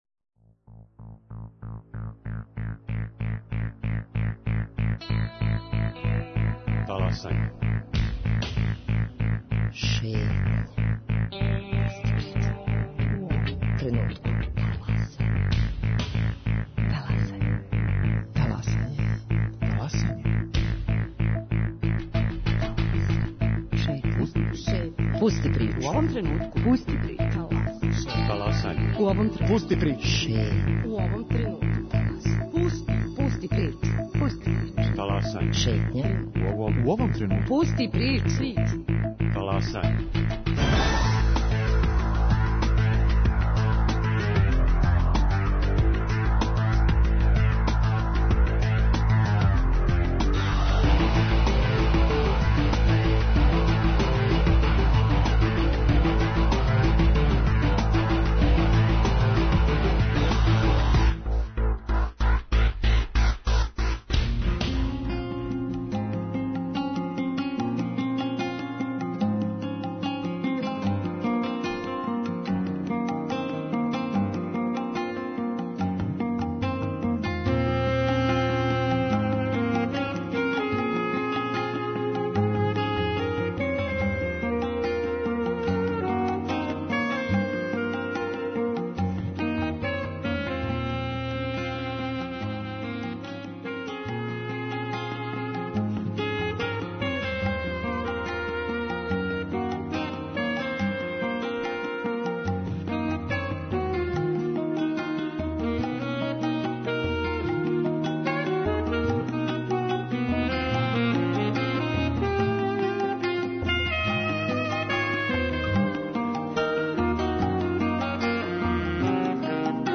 Таласање се уживо емитује из Никшића, где се екипа Радио Београда 1 налази, поводом "Бедем феста".